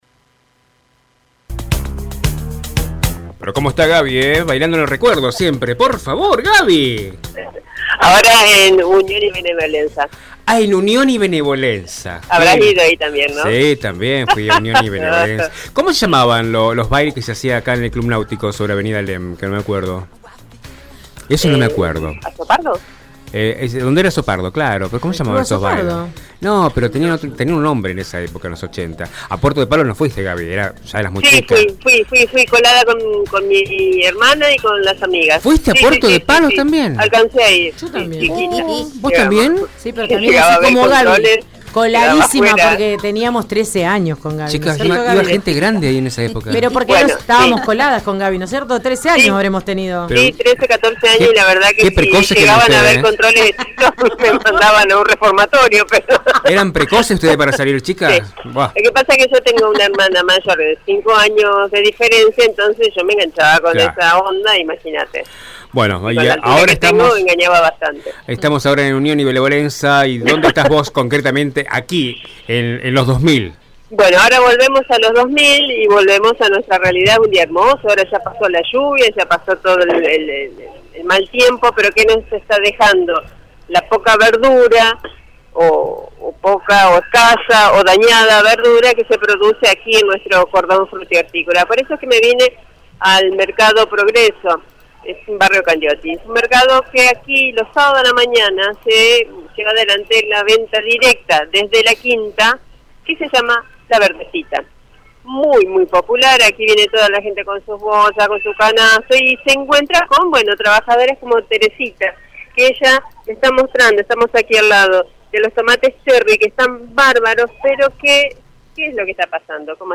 Los antojos del clima en estas últimas semanas fue una situación complicada para los quinteros. El móvil de Radio EME visitó el Mercado Progreso de Santa Fe para investigar si las verduras sufrieron alguna modificación en su precio debido a esta situación.